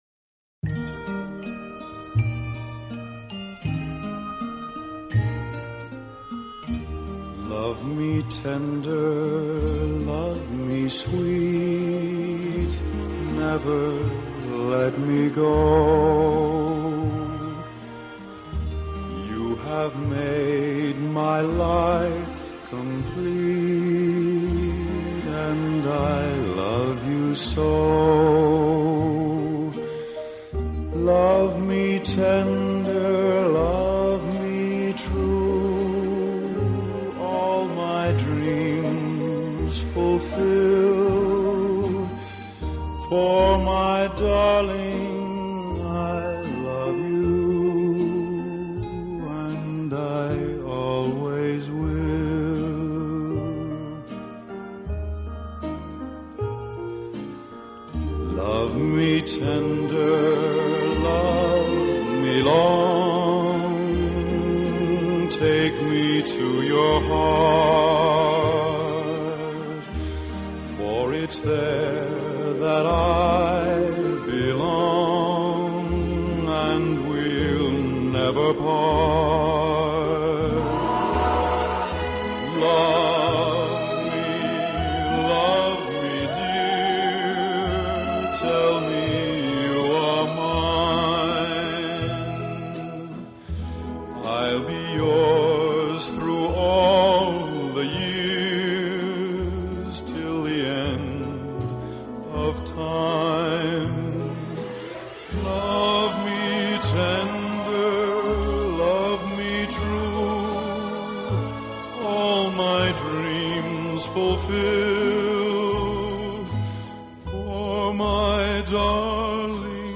【欧美浪漫】
当您在聆听浪漫音乐的时候，优美，舒缓的音乐流水一样缓缓抚过心田，你会觉